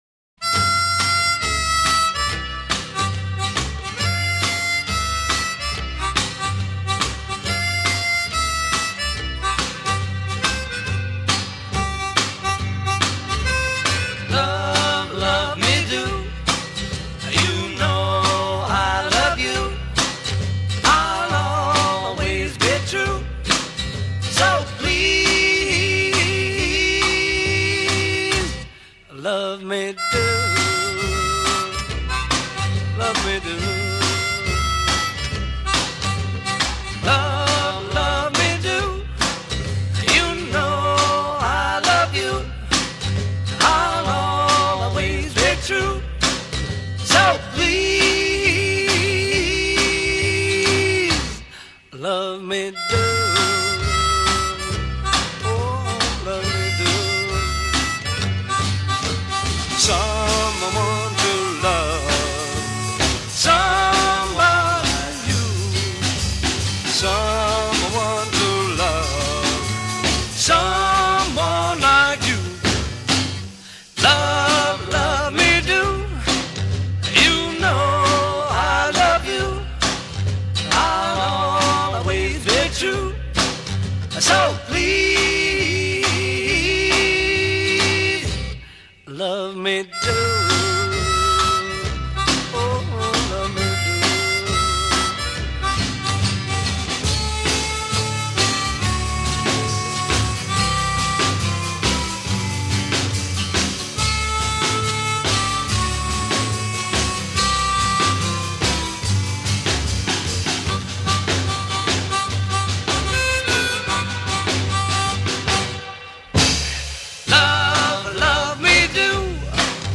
A' Intro 0:00 8 harmonica theme
A verse 0:14 9+4 vocal duet a
B chorus 1:00 8 vocal solo to duet at the end of each line b